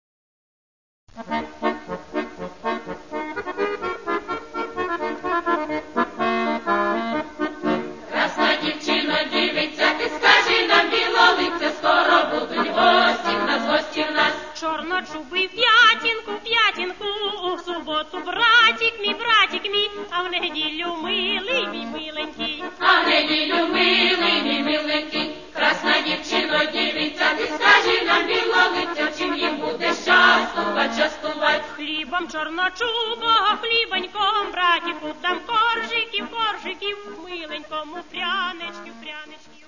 Каталог -> Народна -> Солоспіви та хори